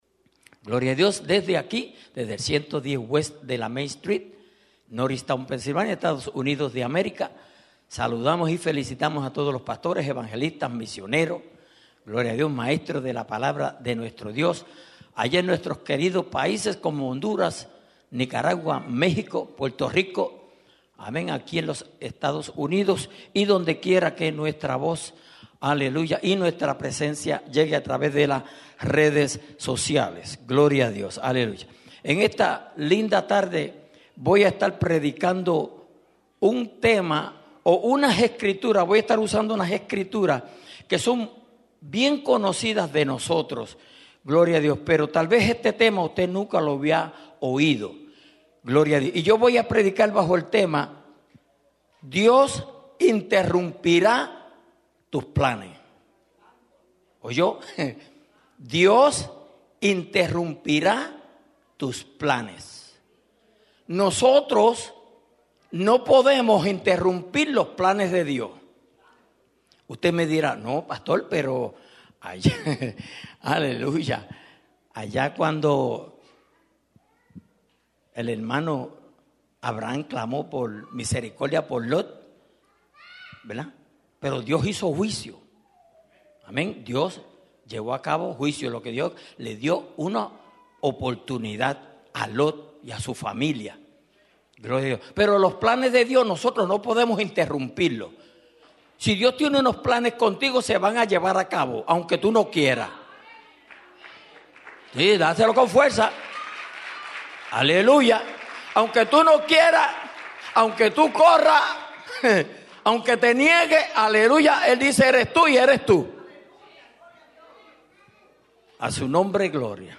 Dios interrumpirá tus planes (Grabado @ Norristown)